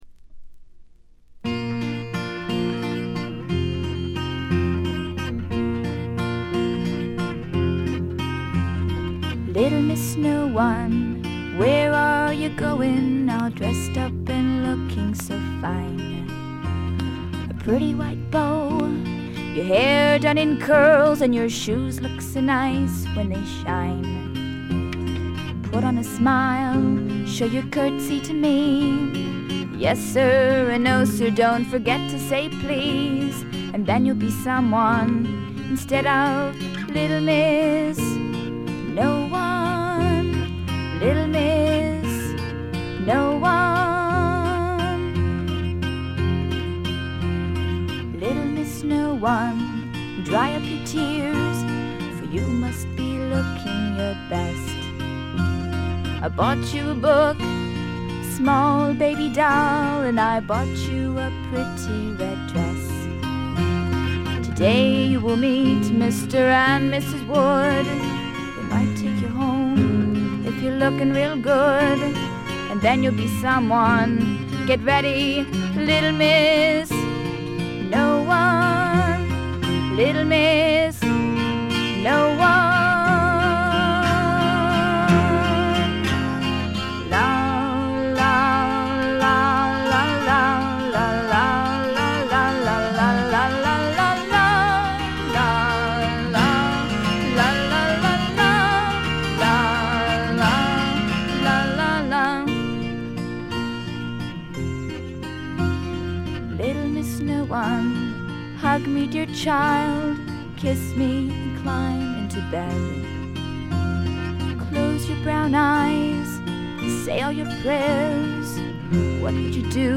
全曲自作の素晴らしい楽曲、清楚な歌声、美しいアコギの音色、60年代気分を残しているバックの演奏、たなびくフルートの音色。
試聴曲は現品からの取り込み音源です。